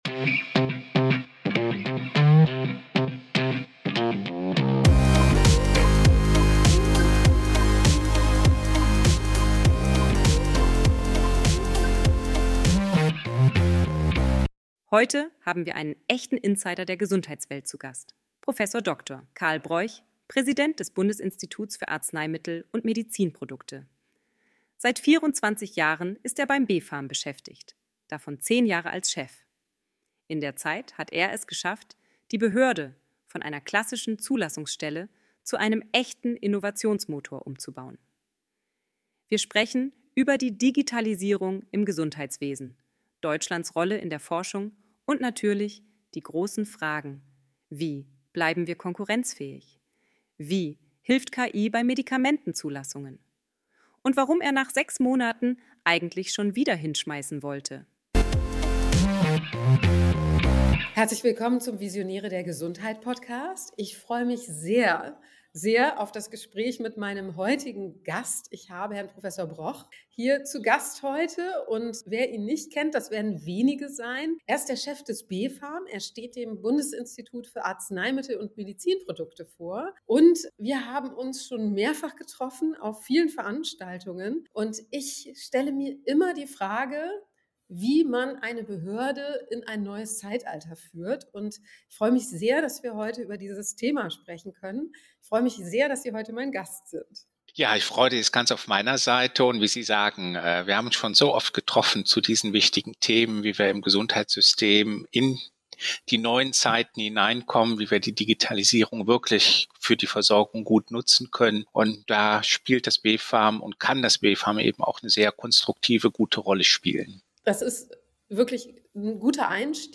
Ein Gespräch mit Prof. Dr. med. Karl Broich, Präsident des Bundesinstituts für Arzneimittel und Medizinprodukte (BfArM).